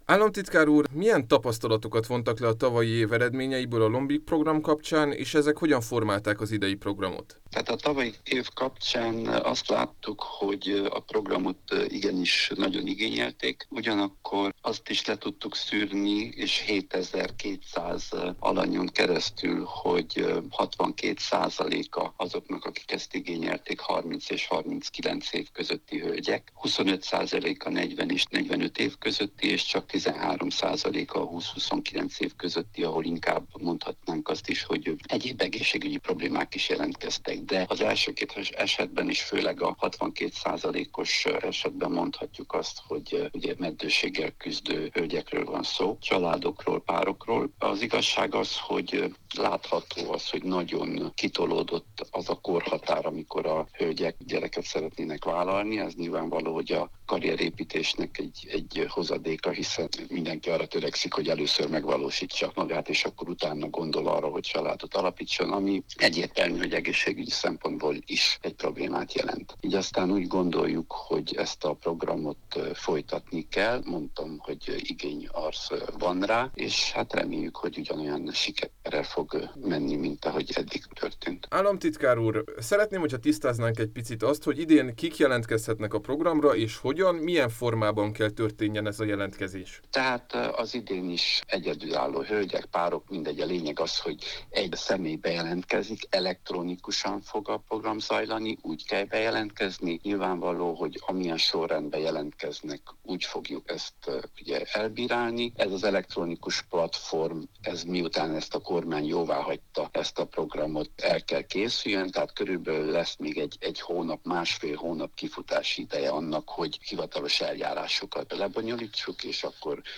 szülész, nőgyógyász és meddőségi specialistát